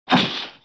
Мультипликационный звук пуф
• Категория: Исчезновение, пропадание
• Качество: Высокое